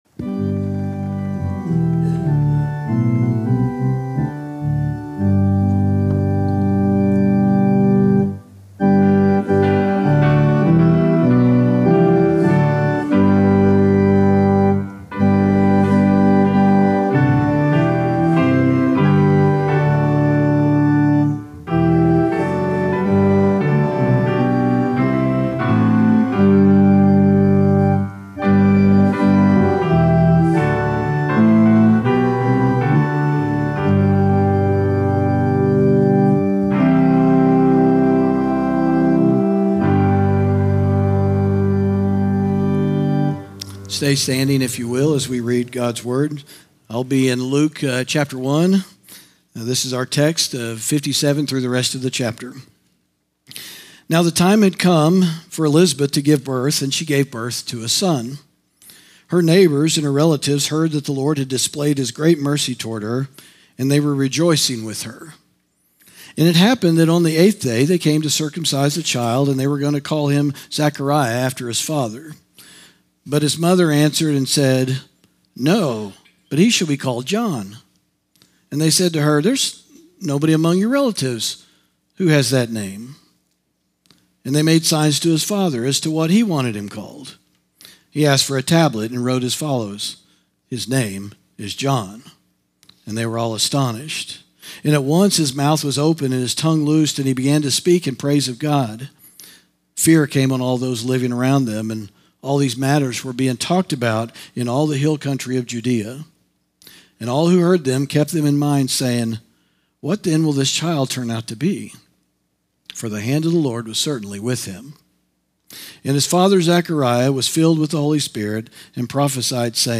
sermon audio 1130.mp3